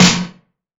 SNARE 099.wav